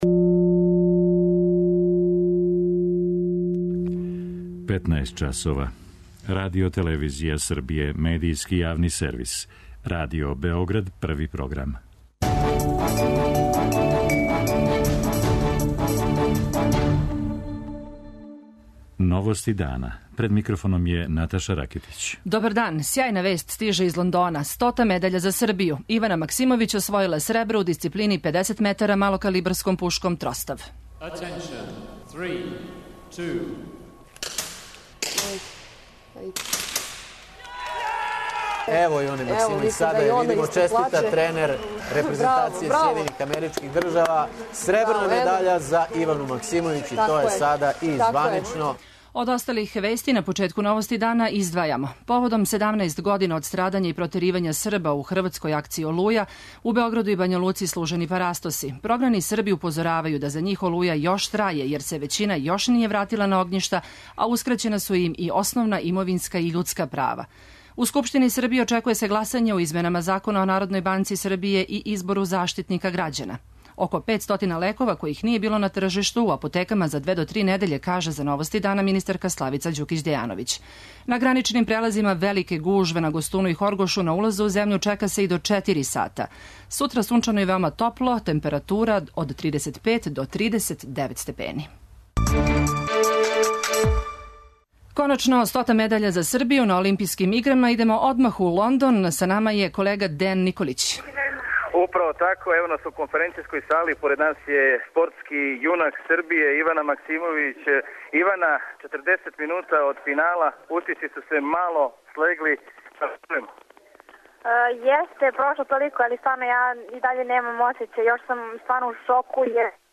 О несташици лекова, листама чекања и другим проблемима у здравству за Новости дана говори нова министарка здравља Славица Ђукић Дејановић.
преузми : 16.02 MB Новости дана Autor: Радио Београд 1 “Новости дана”, централна информативна емисија Првог програма Радио Београда емитује се од јесени 1958. године.